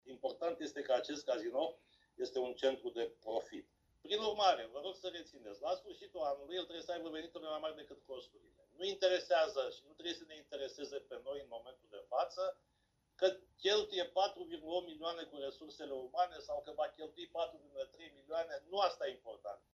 Organigrama societății Patrimoniu Constanța Litoral, cea care va administra și exploata Cazinoul, a stârnit cele mai multe discuții în ședința de miercuri a Consiliului Local Constanța.
Răspunsul a venit de la primarul Vergil Chițac, care susține că înființarea societății s-a făcut în urma unui studiu de fezabilitate.